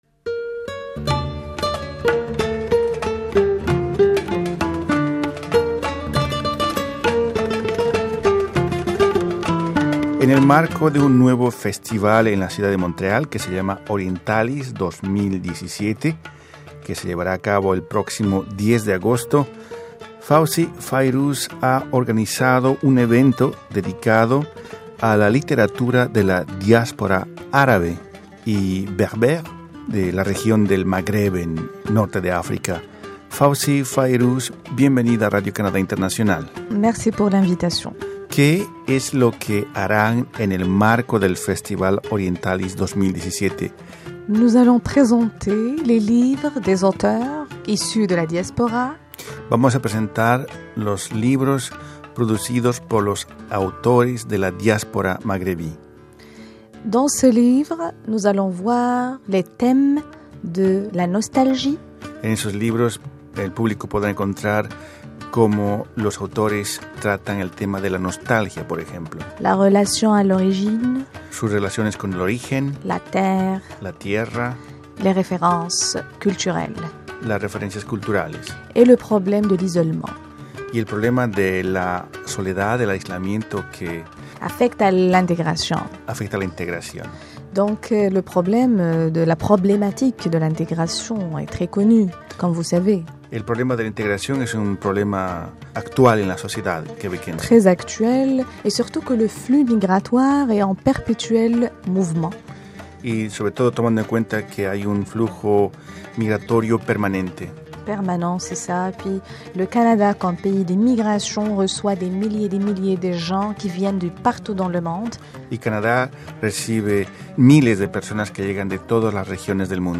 Radio Canadá Internacional pudo conversar con una de las organizadoras de este evento